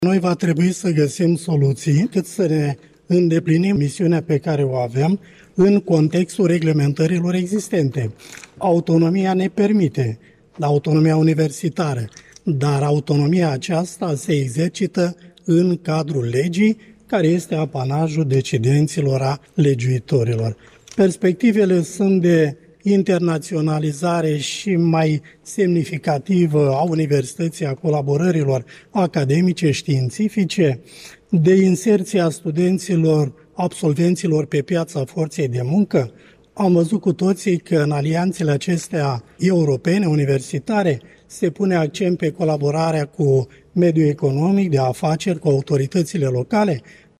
Rectorul Tudorel Toader a vorbit despre proiectele aflate în desfăşurare, proiecte pe care instituţia de învăţământ le va finaliza.